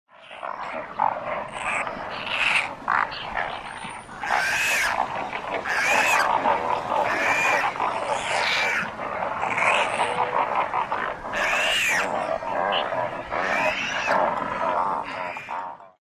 Brown Pelican
Adults are silent, with a rare low croak; nestlings squeal.
brown-pelican-call.mp3